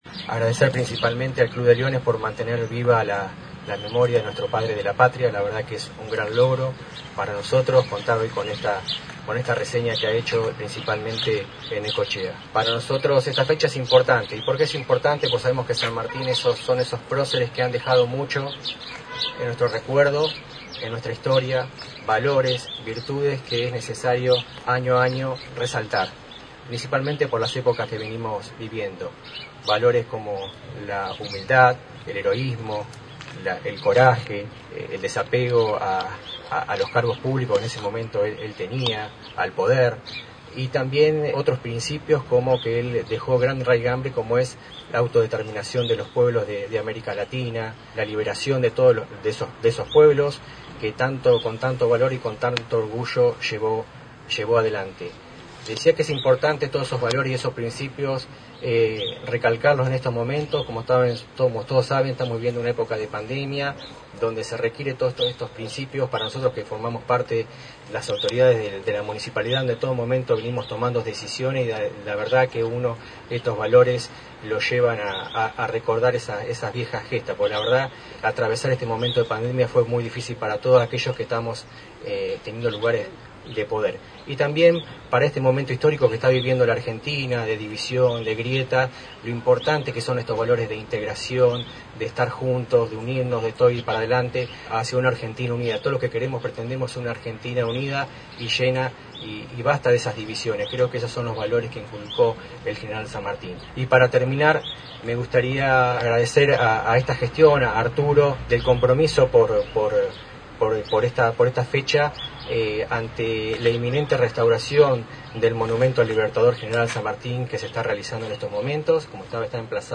A 171 años del Paso a la Inmortalidad del Padre de la Patria, General José de San Martín, se realizó el acto oficial junto al Retoño de San Lorenzo en la plaza de la Villa Balnearia que lleva el nombre del Libertador, ceremonia que fue encabezada por el intendente municipal, Arturo Rojas.
Acto seguido fue el momento de las palabras alusivas, llevadas a cabo por el secretario de Gobierno, Jorge Martínez, y una reseña histórica.